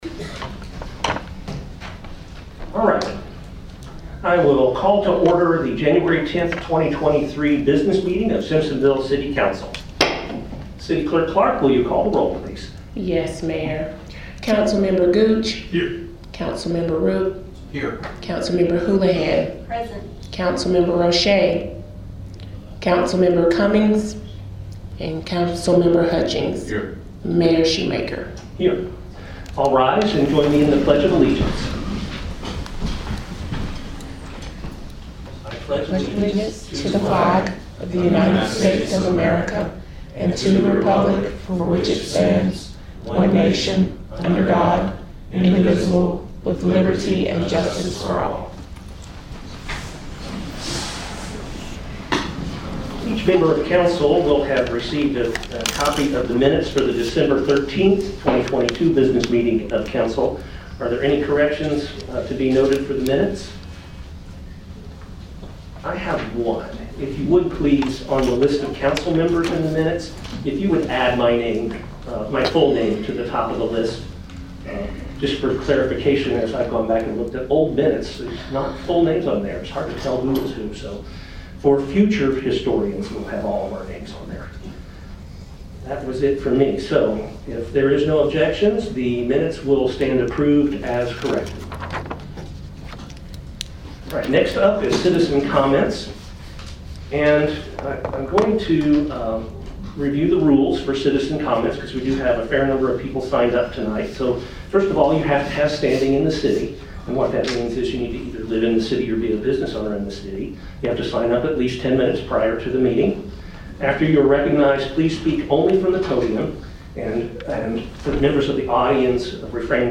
City Council Business Meeting